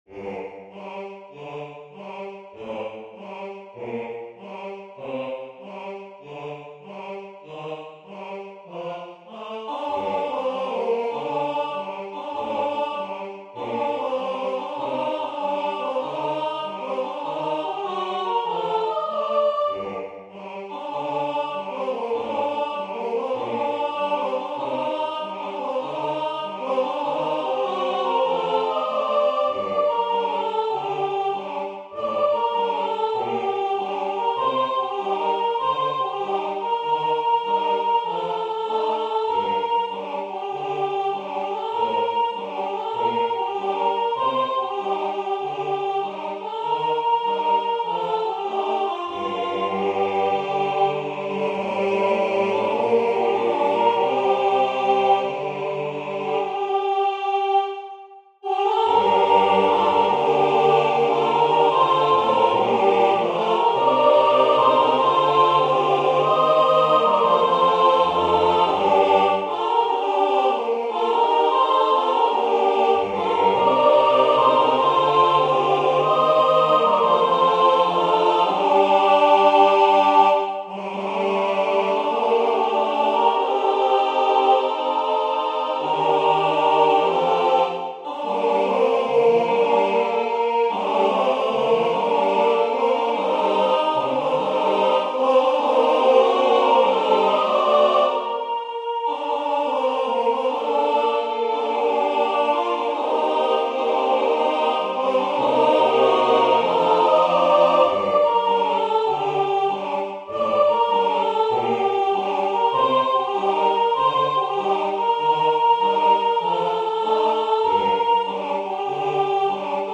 Folk pop
SATB